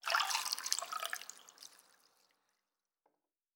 pgs/Assets/Audio/Fantasy Interface Sounds/Food Drink 02.wav at master
Food Drink 02.wav